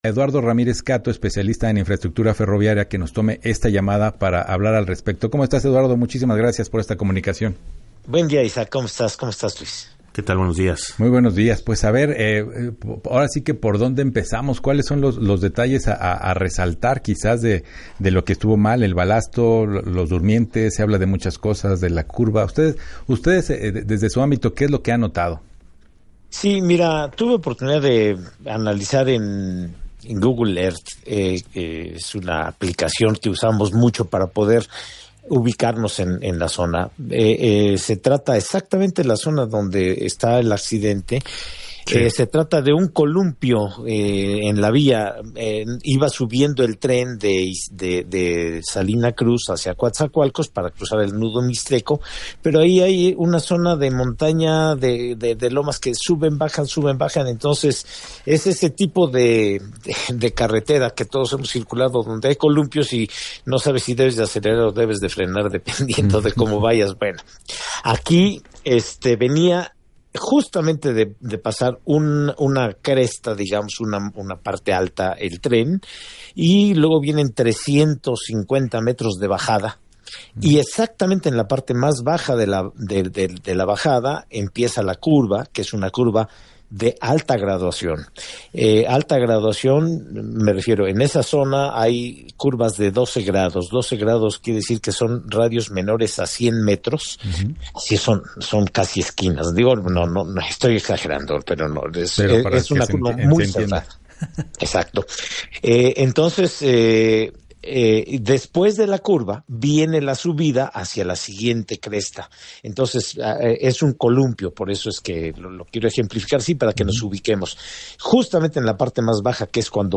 En entrevista para “Así las Cosas”